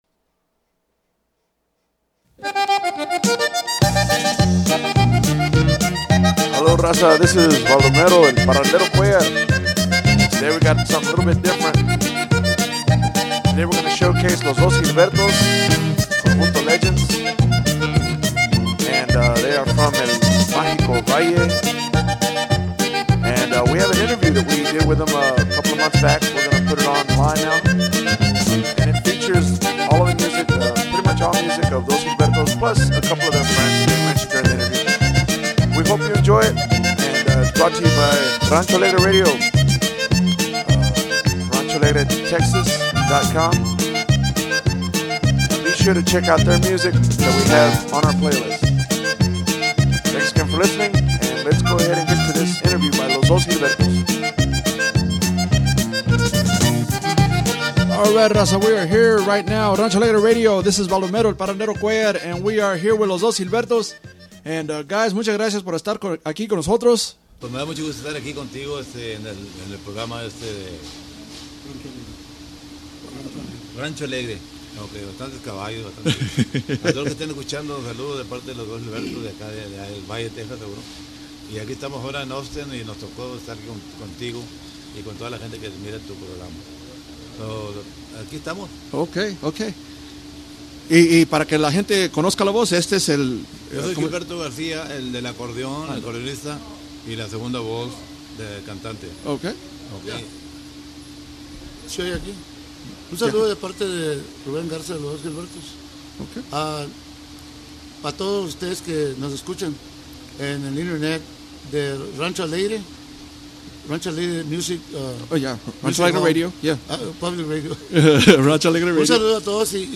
Rancho Alegre Interview - Los Dos Gilbertos